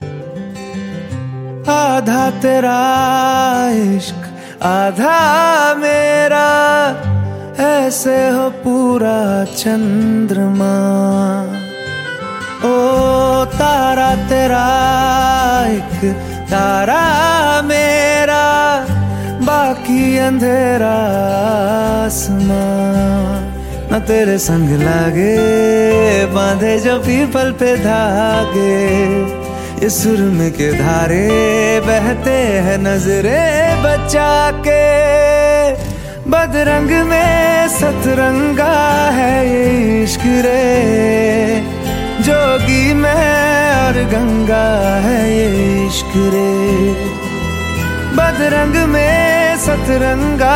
fusion of vibrant beats and mesmerizing melodies
Categories Punjabi Ringtones